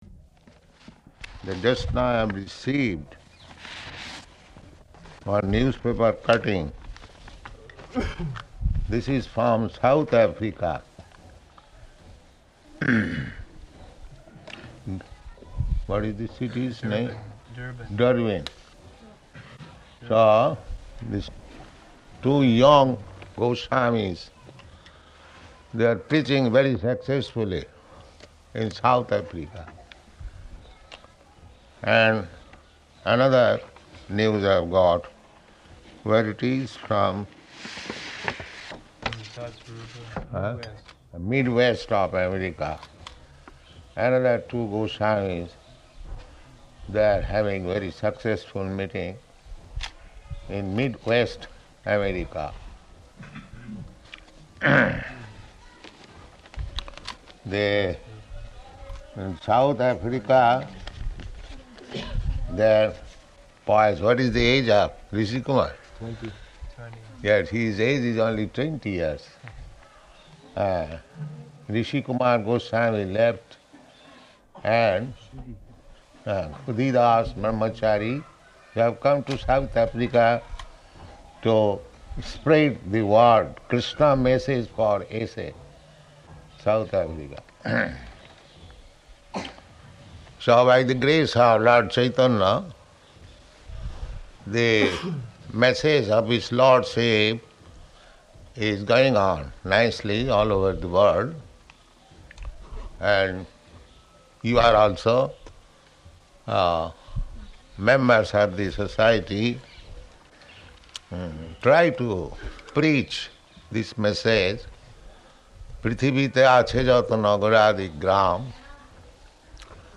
The Nectar of Devotion --:-- --:-- Type: Nectar of Devotion Dated: October 21st 1972 Location: Vṛndāvana Audio file: 721021ND.VRN.mp3 Prabhupāda: ...that just now I have received one newspaper cutting.